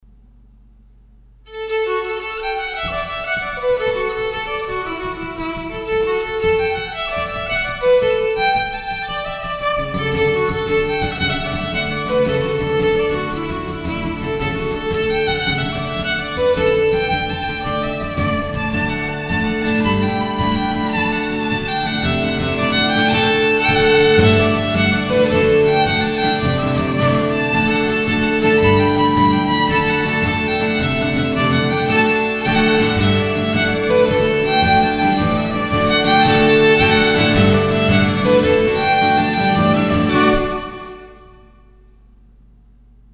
Haste to the Wedding Irish Traditional Violin & Guitar Duo